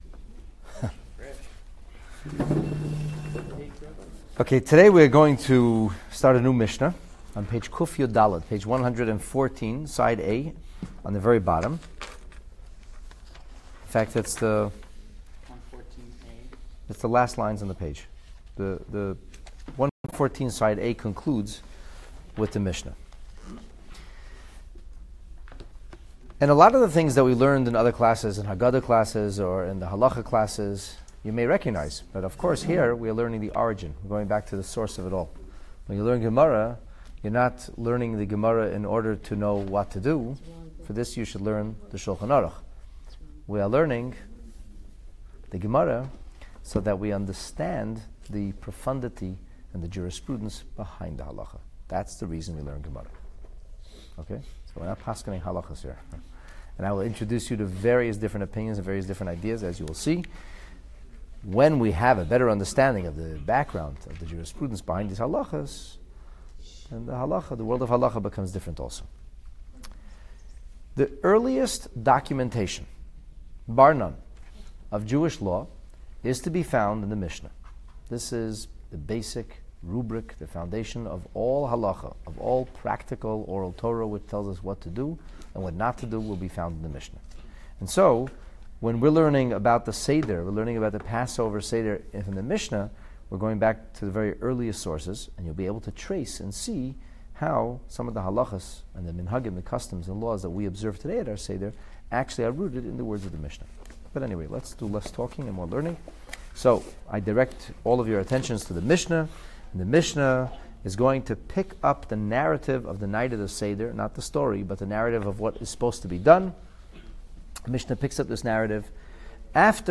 The Talmud on the Pesach Seder, Lesson 7 (Daf/Page 114a) Following the Kiddush wine, the “Order” of the proverbial Passover dinner seems to aimlessly wander away from the typical holiday repast tradition, yet nothing could be further from the truth. Discover the ingeniously choreographed Seder strategy that was designed to piqué children’s curiosity and engage them in an annual sacred multi-media educational program.